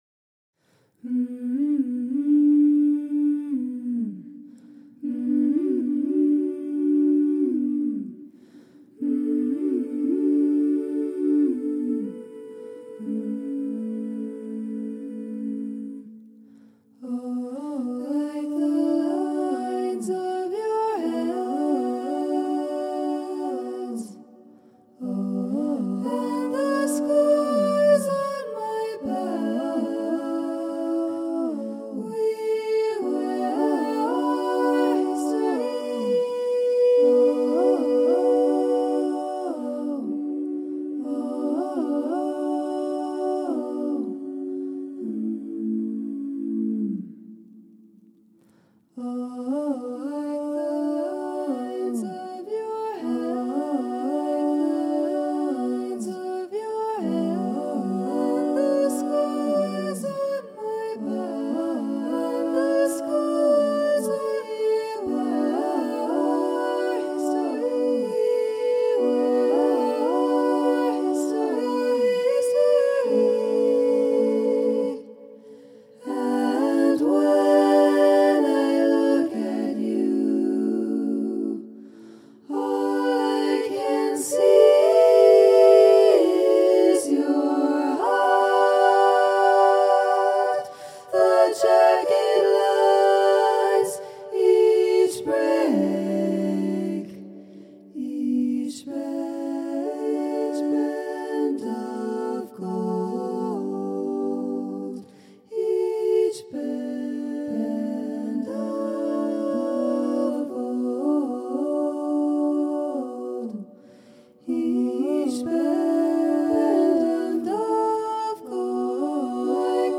SSAA a cappella choir